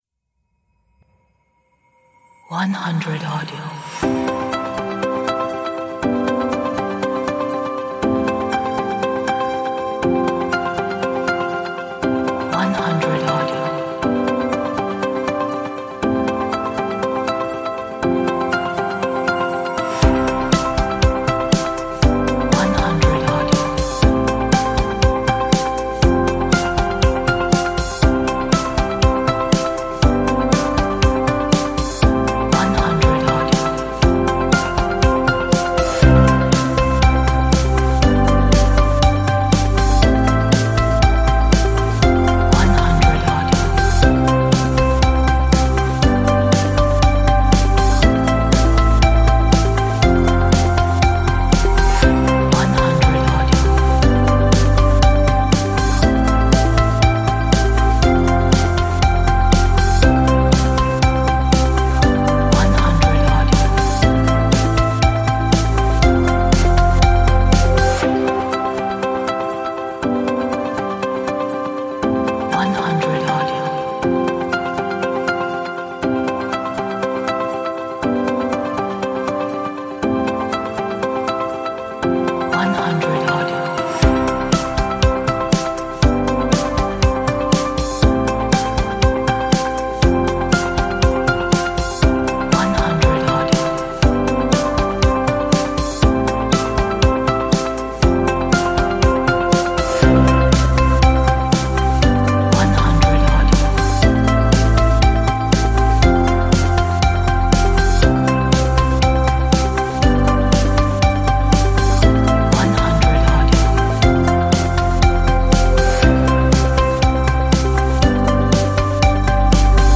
a nice pop upbeat
这是一首 很好的 乐观的流行音乐